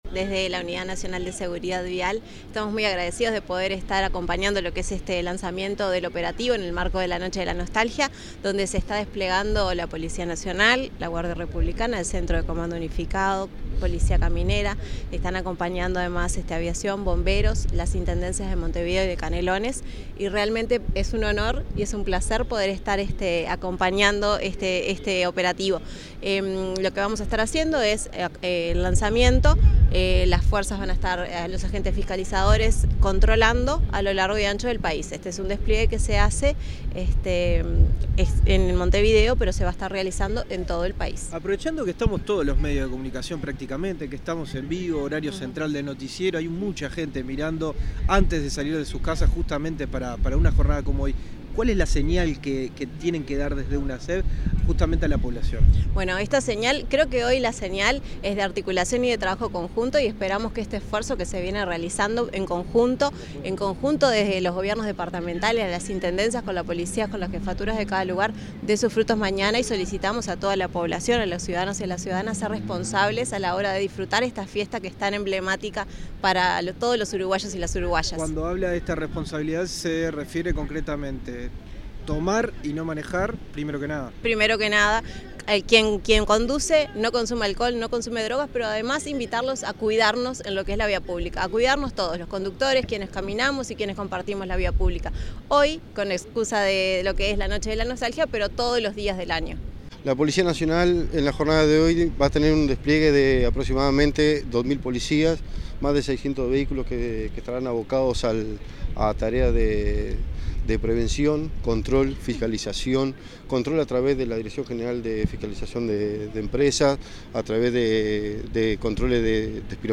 Declaraciones de la secretaria de Unasev, María Fernanda Artagaveytia, y el jefe del Estado Mayor, Kerman Da Rosa
Declaraciones de la secretaria de Unasev, María Fernanda Artagaveytia, y el jefe del Estado Mayor, Kerman Da Rosa 24/08/2025 Compartir Facebook X Copiar enlace WhatsApp LinkedIn La secretaria general ejecutiva de la Unidad Nacional de Seguridad Vial (Unasev), María Artagaveytia, y el jefe del Estado Mayor, Kerman Da Rosa, dialogaron con la prensa en el comienzo del operativo especial de seguridad y control por la Noche de la Nostalgia.